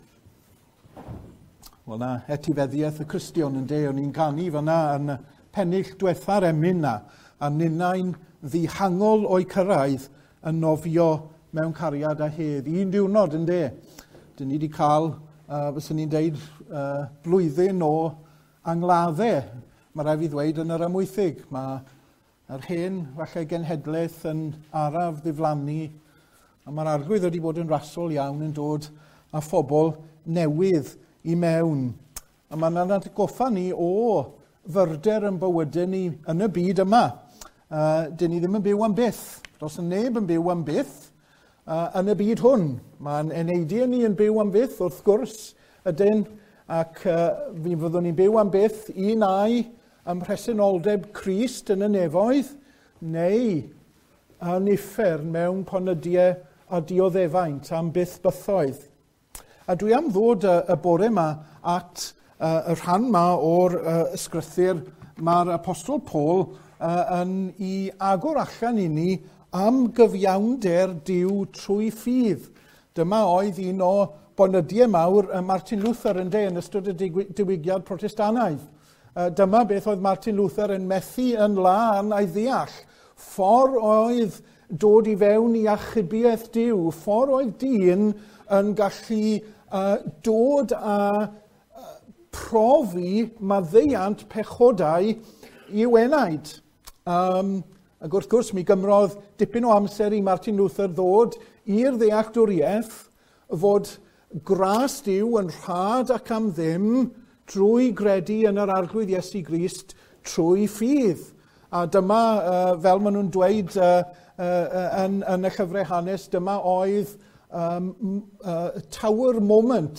neges o cyfres “Pregethwr Gwadd.”